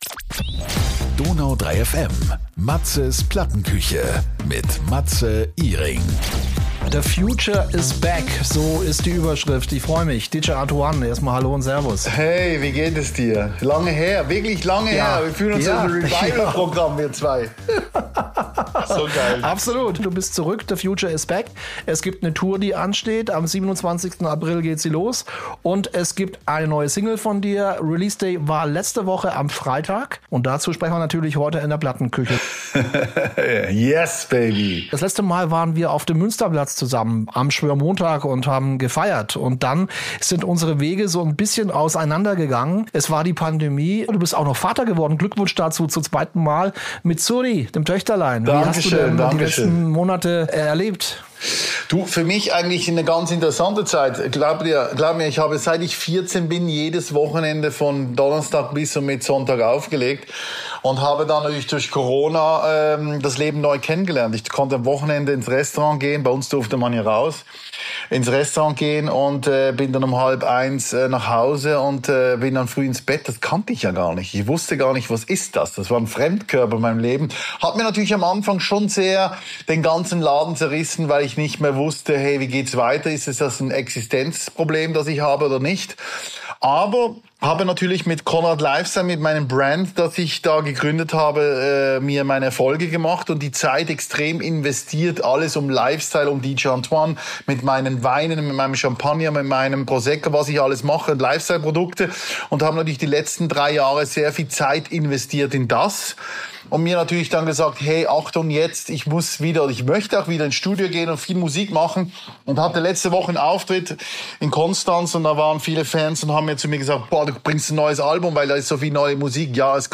Lange war es eher ruhig um ihn, jetzt ist er aber endlich wieder mit neuer Musik zurück: DJ Antoine ist zu Gast in der Plattenküche!